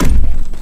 SoldierSoundsPack
kick.wav